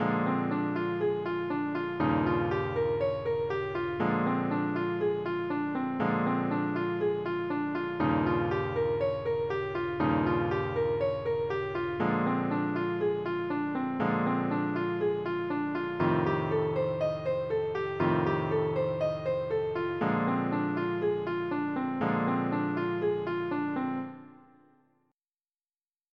blues changes
It simply demonstrates playing 1357 over each chord in the progression.